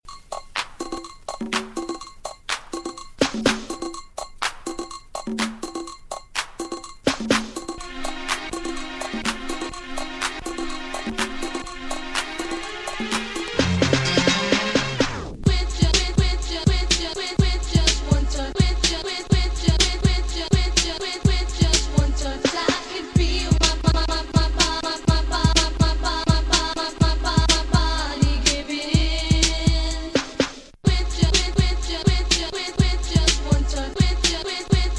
Жанр: R&B / Соул / Фанк